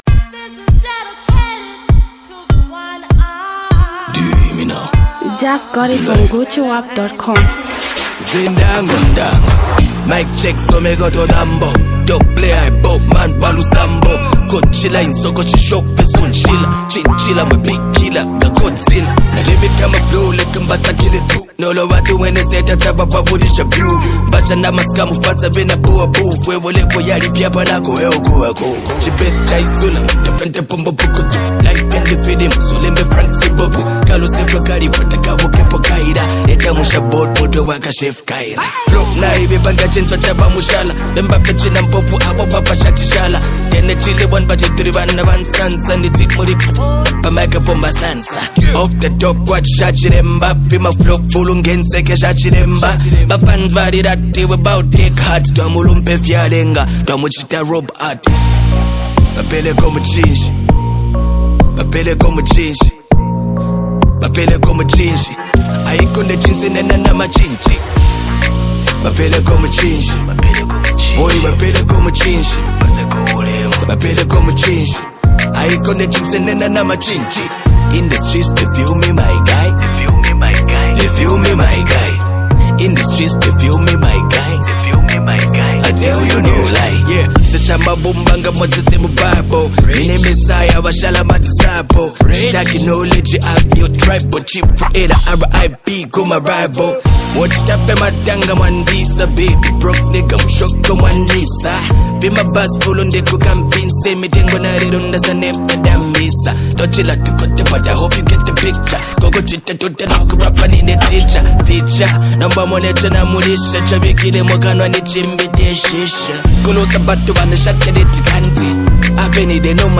Zambian Mp3 Music
dancehall & reggae
singer, songwriter & rapper.
impressive hit melodic sound